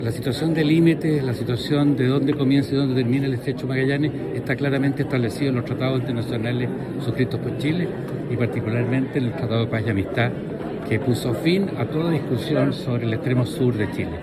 El tema generó reacciones en el parlamento e incluso fue abordado en la Comisión de Defensa de la Cámara de Diputados.
En la instancia, el ministro de Defensa, Fernando Barros, aseguró que la soberanía chilena no se encuentra en discusión.